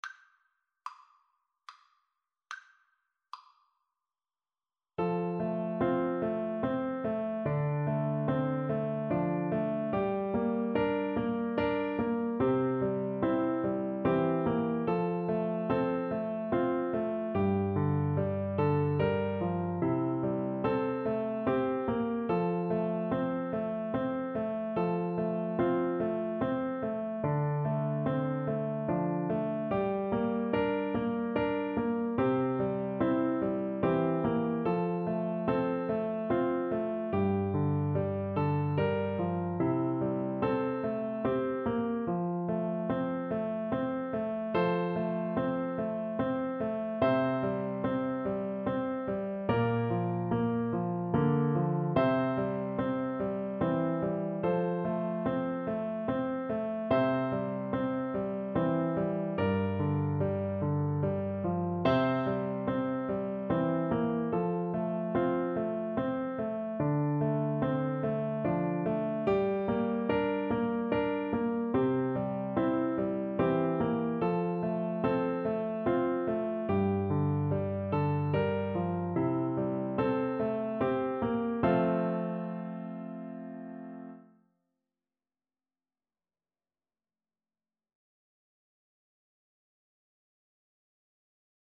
F major (Sounding Pitch) (View more F major Music for Bassoon )
3/4 (View more 3/4 Music)
Traditional (View more Traditional Bassoon Music)